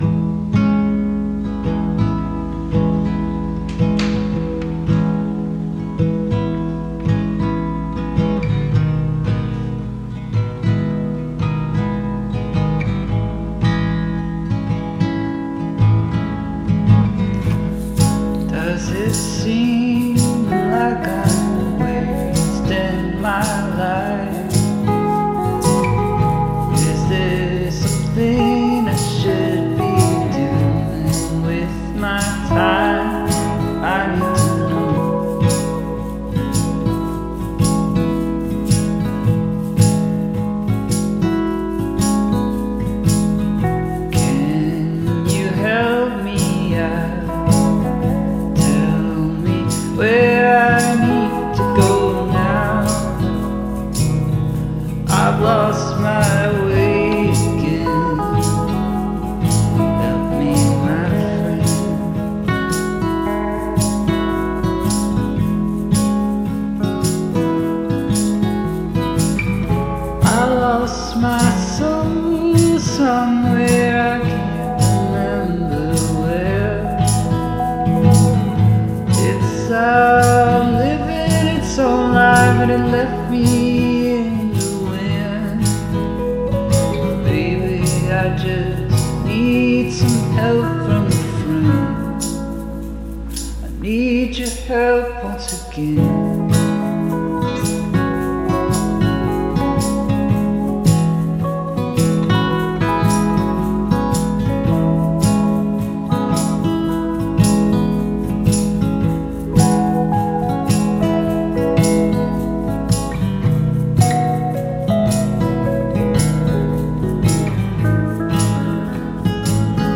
Track7_Soothing.mp3